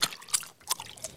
Potion Drink (4).wav